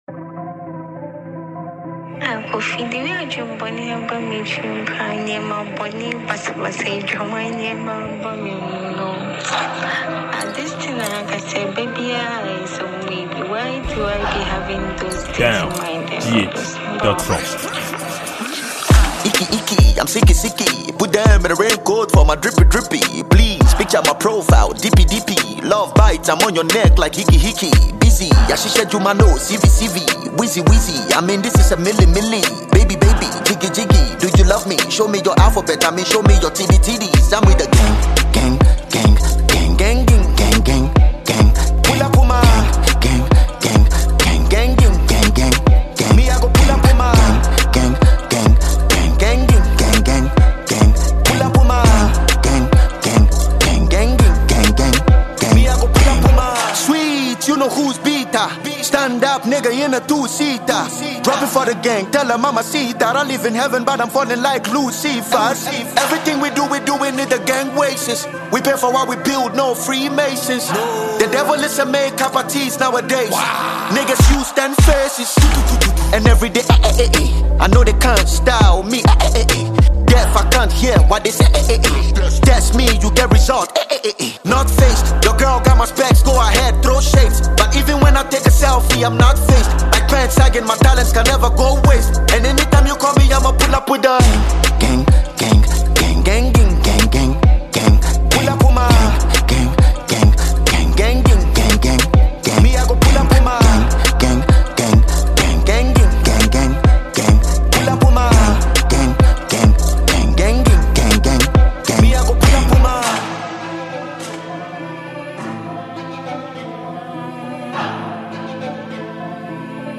Genre: Hiphop
outputs a high-tempo beats with bass